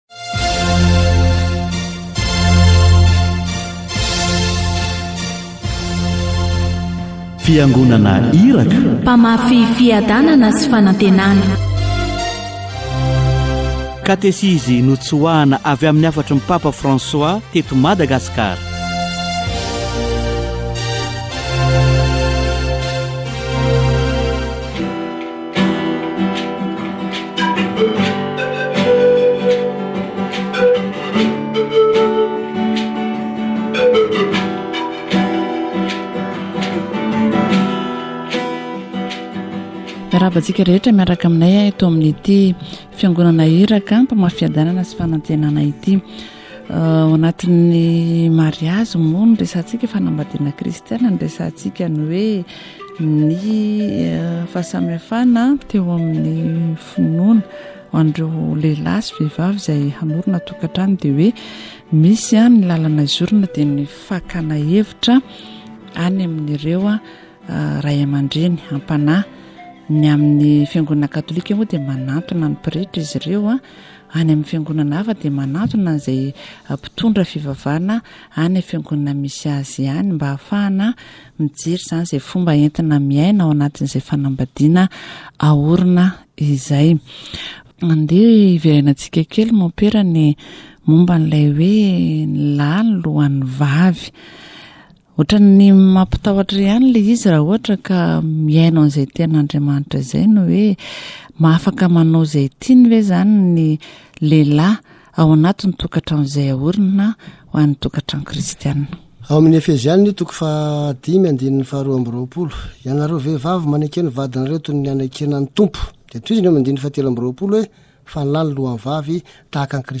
The husband is the head of the wife, as Christ is the head of the Church, which is his body, and of which he is the Savior. This should be reflected in the performance of the duties and organization of the home. Catechesis on vocational discernment